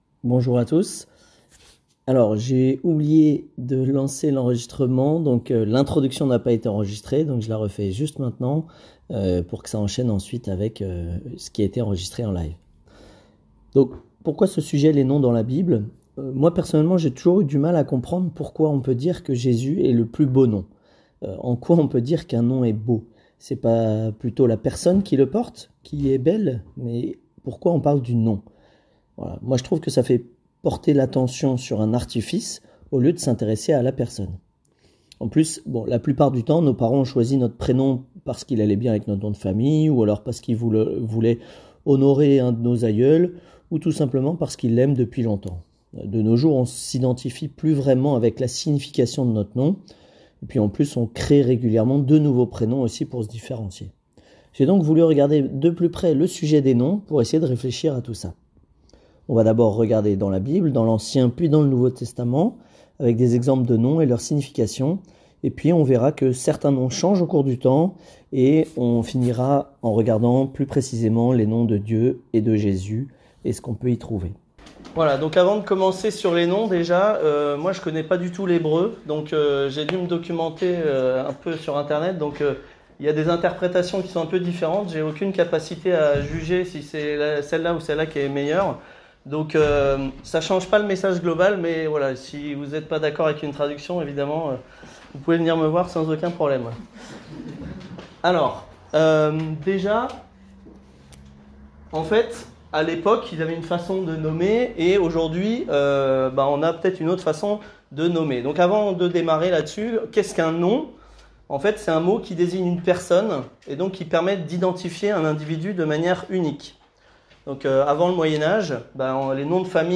Voir le slide de la prédication https